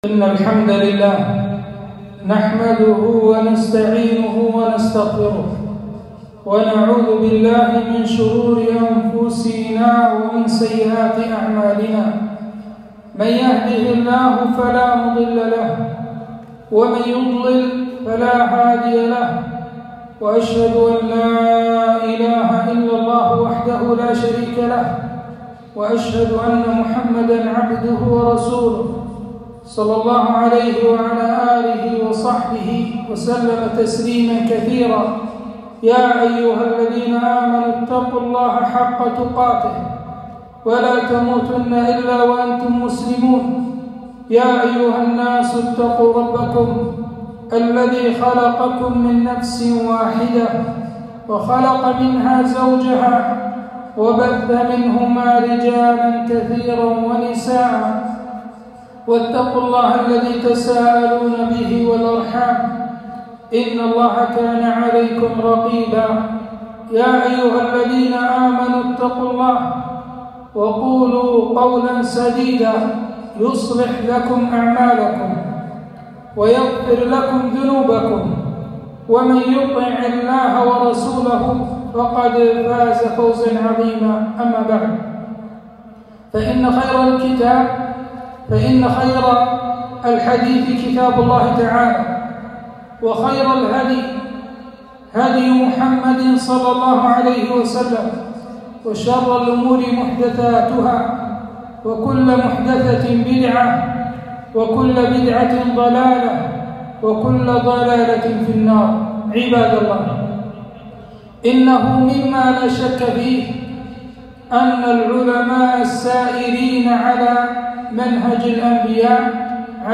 خطبة - وقفات مع سيرة الإمام البخاري -رحمه الله-